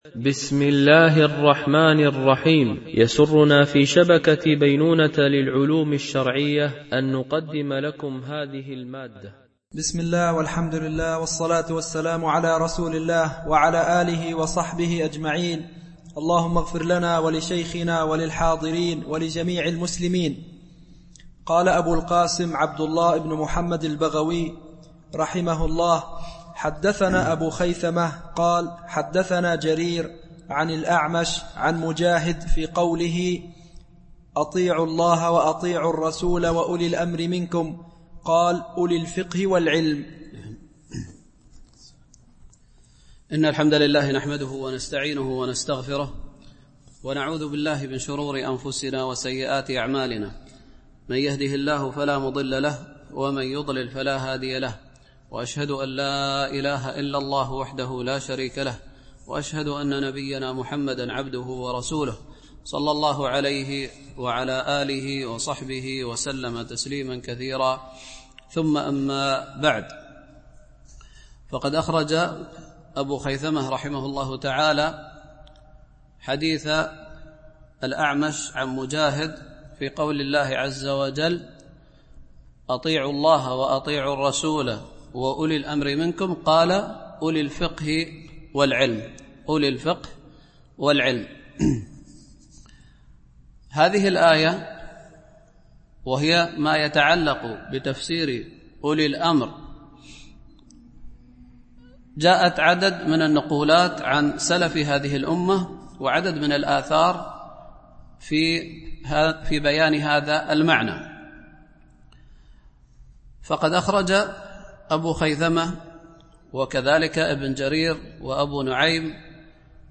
شرح كتاب العلم لأبي خيثمة ـ الدرس 21 (الأثر 62-64)